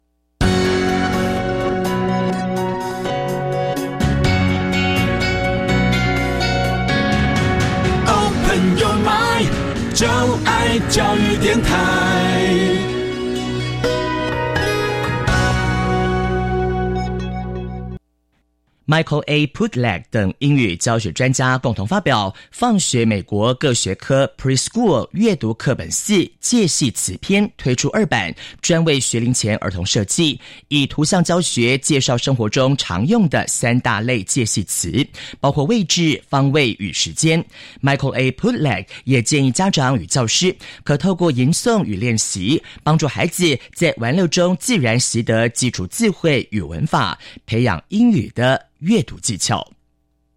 同時，可搭配MP3中曲調輕快、富含韻律的Chant（吟誦）一起學習，讓孩子能朗朗上口跟著唸誦，更容易模仿與記憶。
課文內容由專業播音員錄製，以Read（朗讀）或Chant（吟誦）呈現。老師或家長可帶著孩子一起聆聽，跟著唸誦，搭配時而出現的有趣音效聲與歌曲，孩子不會感到無趣，學習效果加倍！